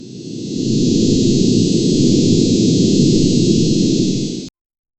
Royalty-free aerodynamics sound effects